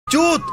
Listen to CHODU CID CHUT, a memes sound button featuring chodu, choducid, choducidchut on InstantSFX.